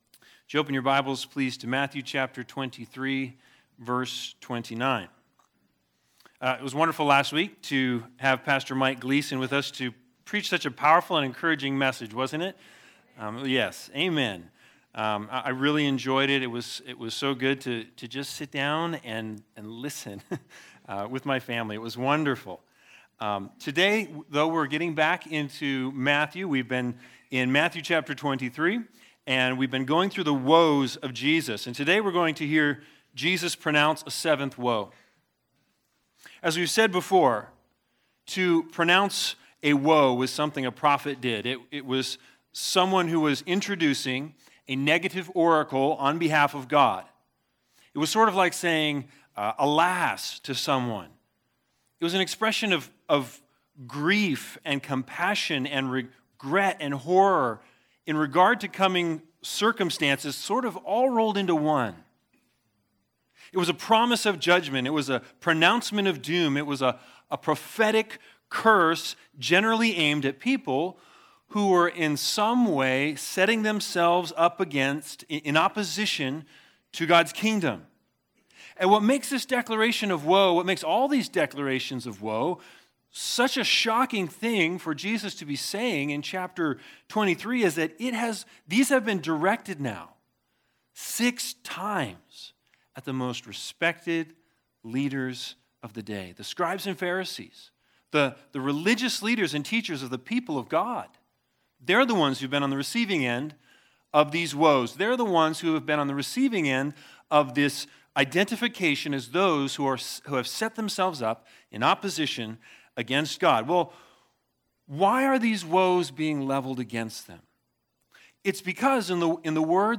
Matthew 23:29-36 Service Type: Sunday Sermons The Big Idea